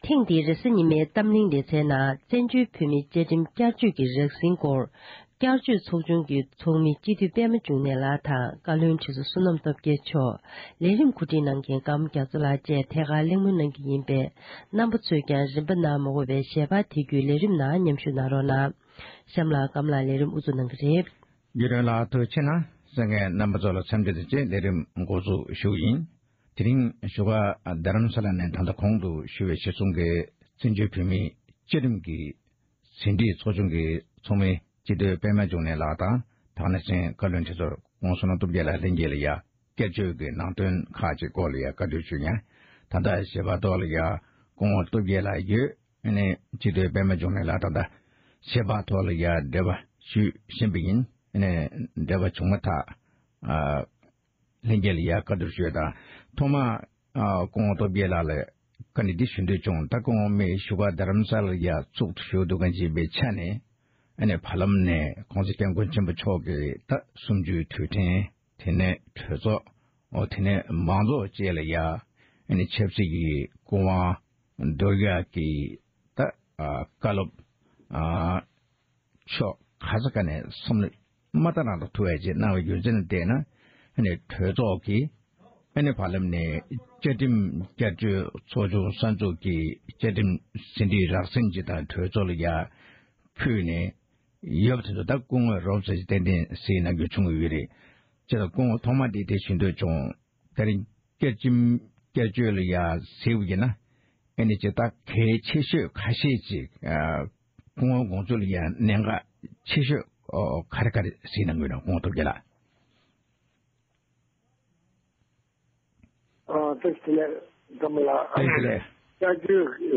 བཙན་བྱོལ་བོད་མིའི་བཅཀའ་ཁྲིམས་བསྐྱར་བཅོས་ཀྱི་ཟིན་བྲིས་རག་པའི་སྐོར་གླེང་བ།